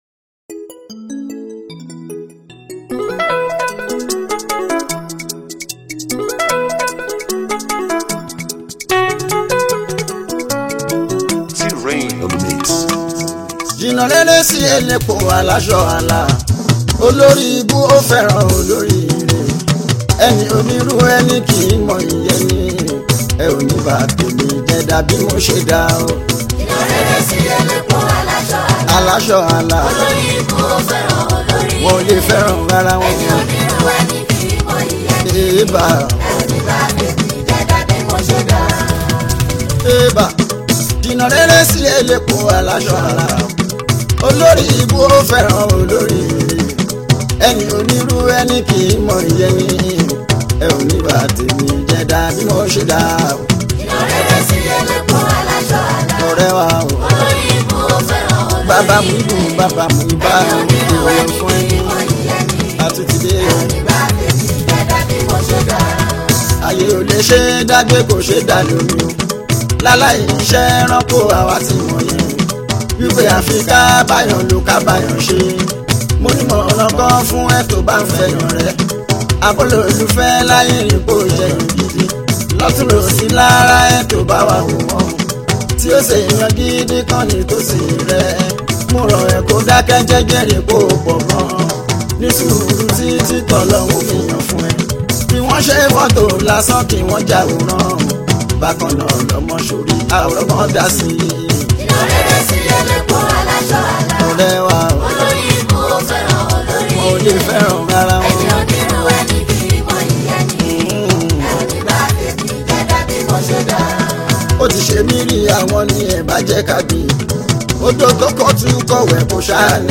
Nigerian Yoruba Fuji track
Yoruba Fuji Sounds